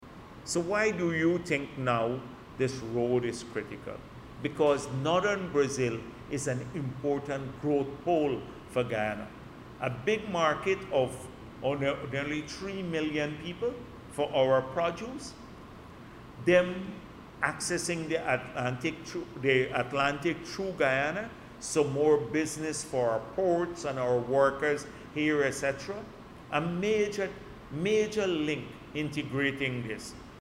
During a recent press conference, Vice President Dr. Bharrat Jagdeo emphasized the pivotal role that northern Brazil will play in driving Guyana’s ongoing development and prosperity.